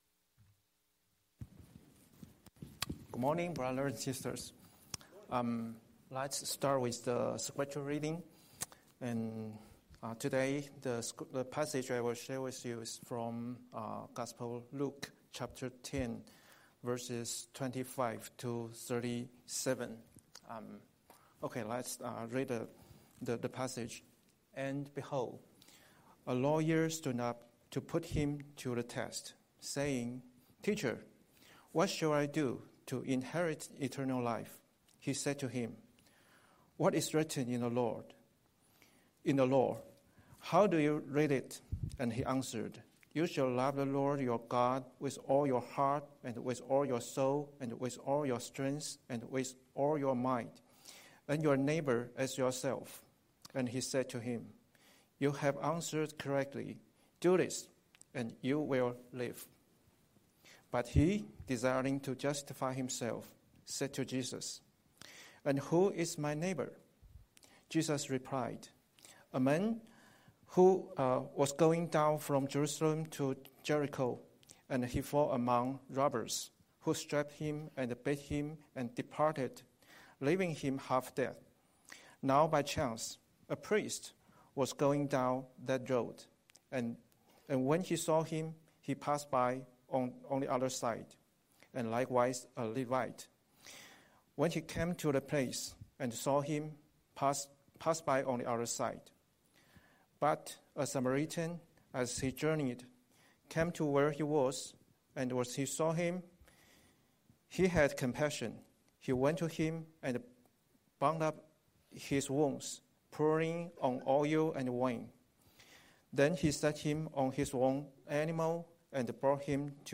Scripture: Luke 10:25-37 Series: Sunday Sermon